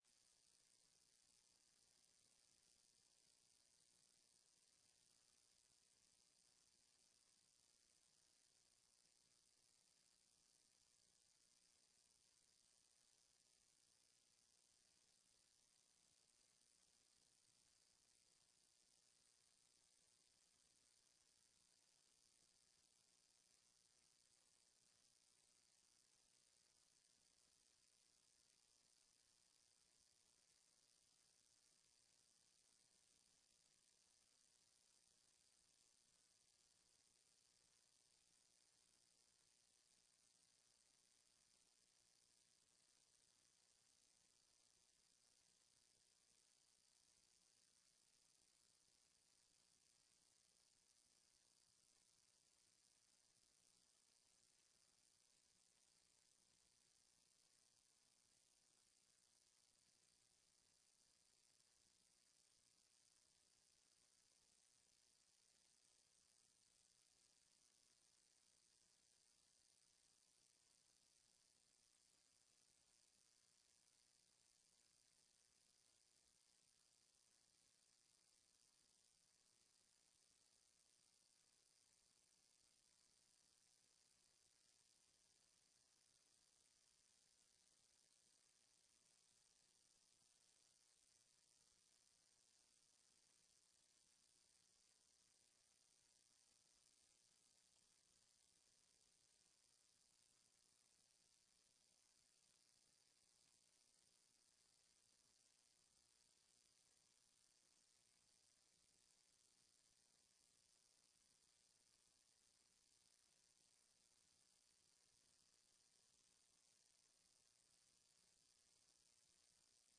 Sessão plenária do dia 14/10/15